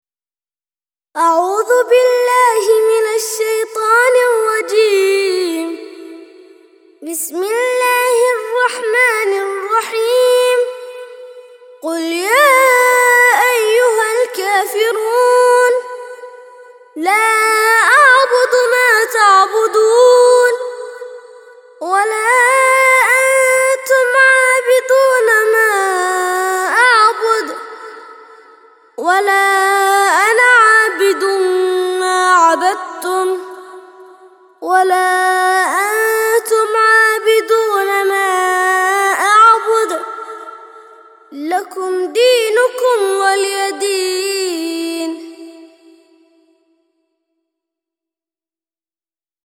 109- سورة الكافرون - ترتيل سورة الكافرون للأطفال لحفظ الملف في مجلد خاص اضغط بالزر الأيمن هنا ثم اختر (حفظ الهدف باسم - Save Target As) واختر المكان المناسب